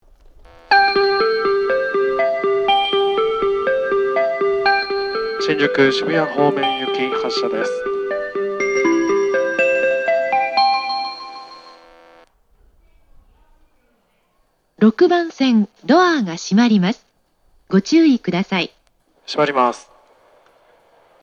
発車メロディー
フルコーラスです。採時駅ですので余韻まで鳴りやすいです。